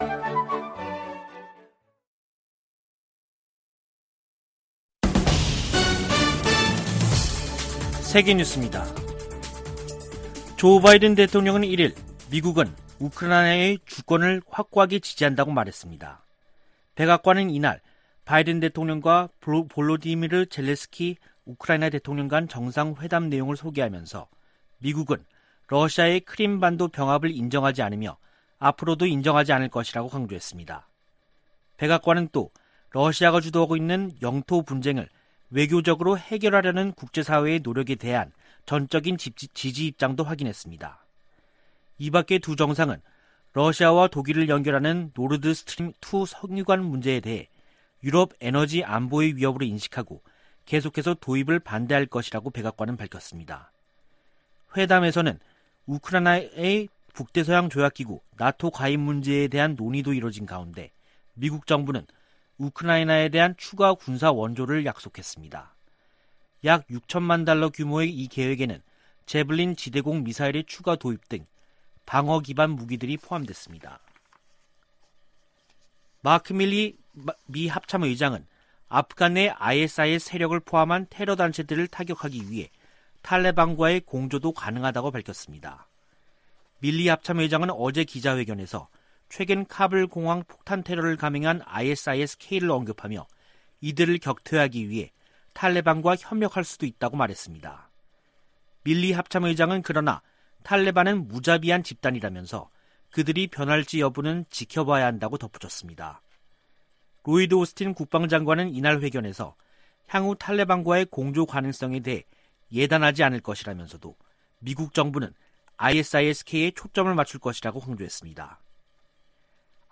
VOA 한국어 간판 뉴스 프로그램 '뉴스 투데이', 2부 방송입니다. 미 국무부가 미국인들의 북한 여행금지 조치를 1년 더 연장하기로 했습니다. 미국의 구호 단체들과 이산가족 단체들은 북한 여행금지 재연장에 실망을 표시했습니다. 미 공화당 의원들은 영변 핵 시설 재가동은 북한이 미국과 선의로 비핵화 협상을 할 의향이 없다는 증거라며, 북한에 최대 압박을 가해야 한다고 바이든 행정부에 촉구했습니다.